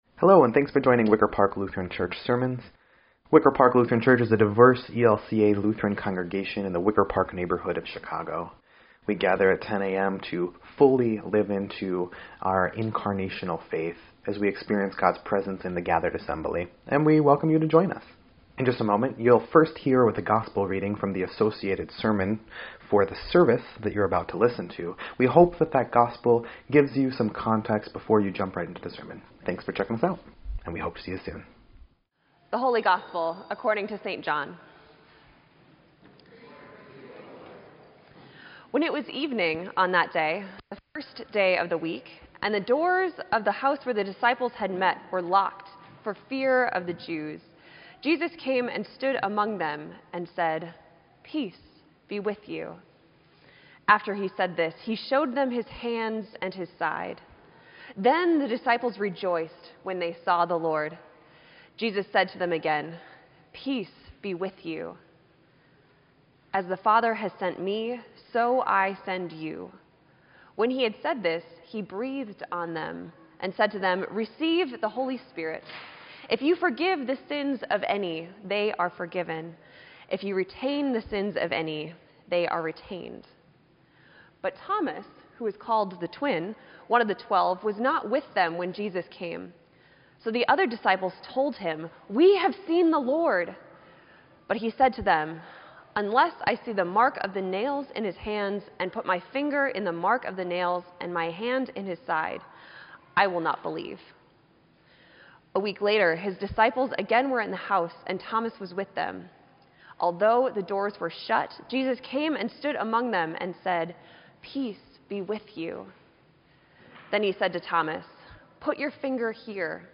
Sermon_4_8_18_EDIT.mp3